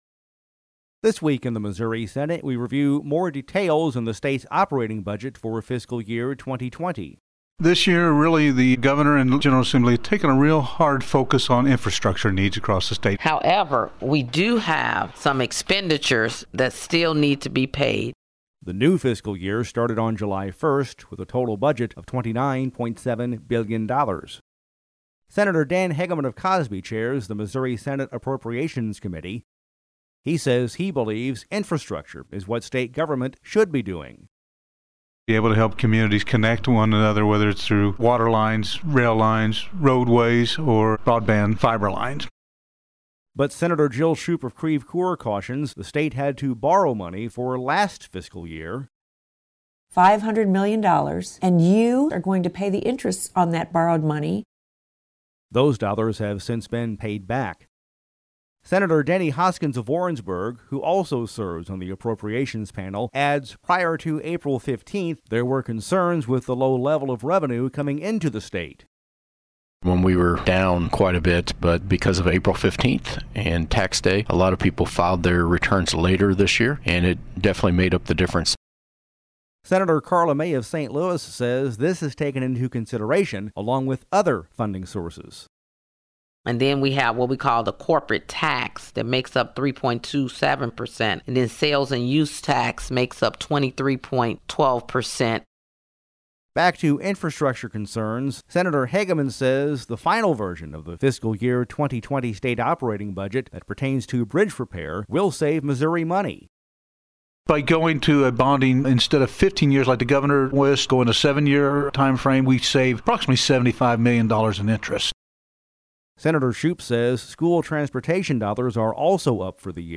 July 5: This Week in the Missouri Senate reviews more details about the state operating budget for Fiscal Year 2020, which started on Monday (7/1). We’ve included actualities from Missouri Senate Appropriations Committee Chair, Sen. Dan Hegeman, R-Cosby; Sen. Jill Schupp, D-Creve Coeur; Sen. Denny Hoskins, R-Warrensburg; and Sen. Karla May, D-St. Louis, in this feature report